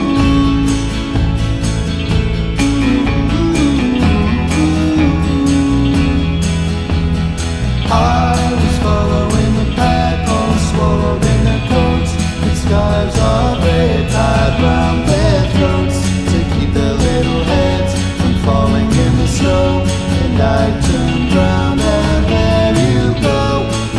no Backing Vocals Indie / Alternative 2:32 Buy £1.50